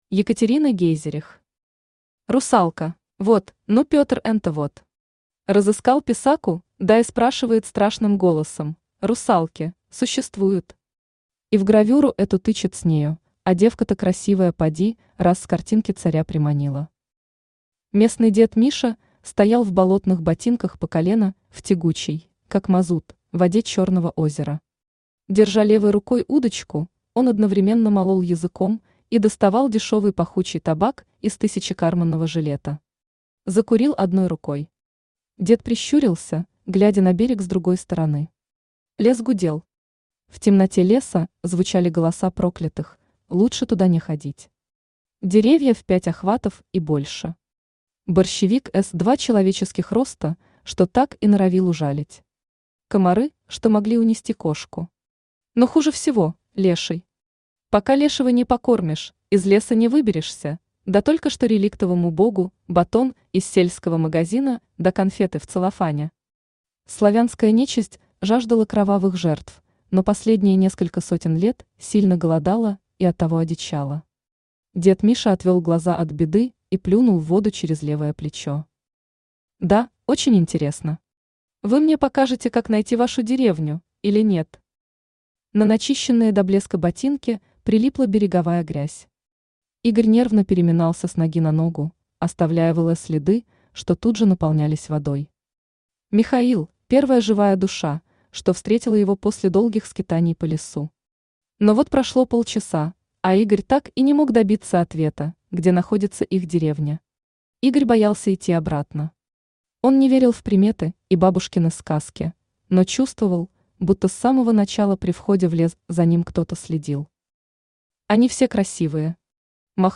Аудиокнига Русалка | Библиотека аудиокниг
Aудиокнига Русалка Автор Екатерина Гейзерих Читает аудиокнигу Авточтец ЛитРес.